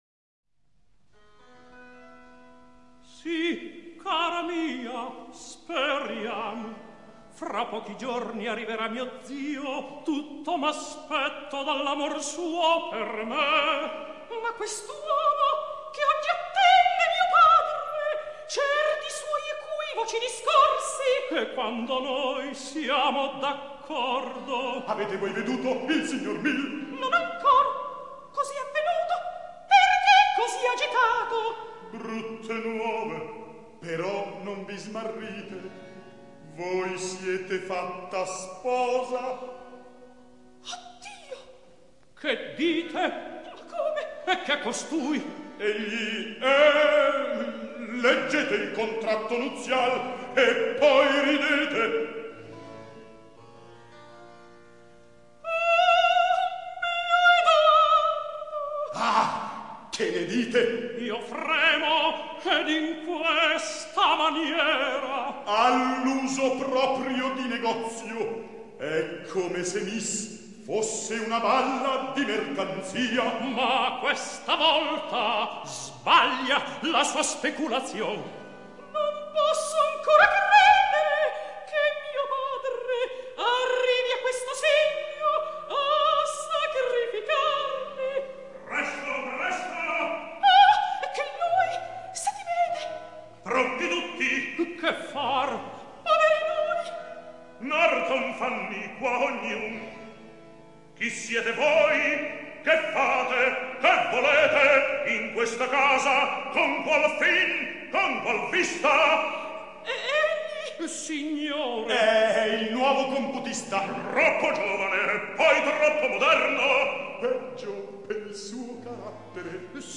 registrazione in studio.